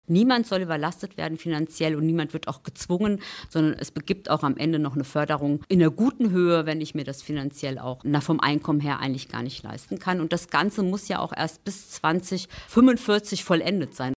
Ein drittes Thema in unserem Radio Siegen-Sommerinterview waren unsere Heizungen.